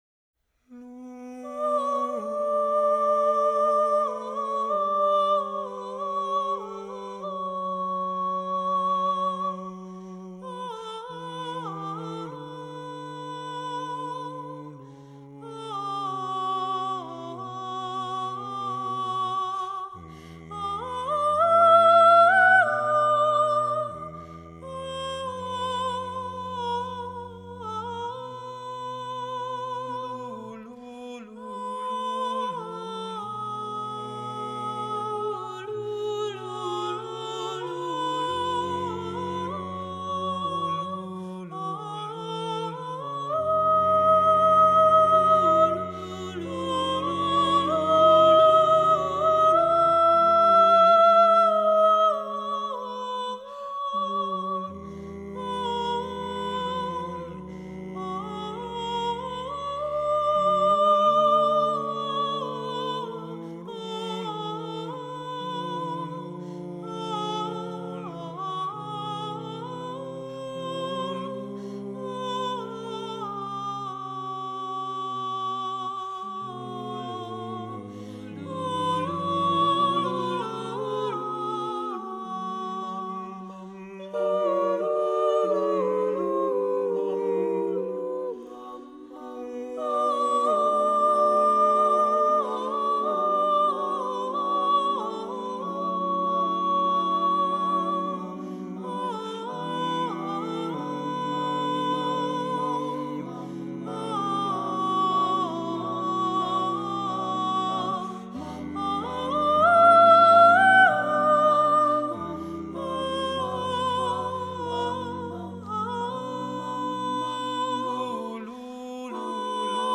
SATB, S solo, a cappella
This is my a cappella version